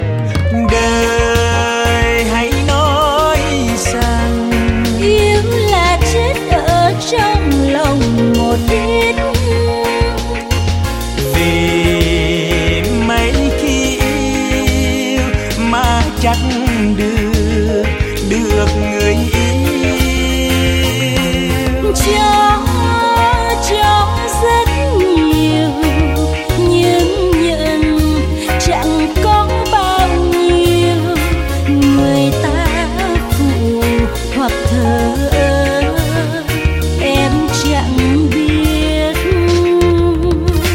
Nhạc Bolero.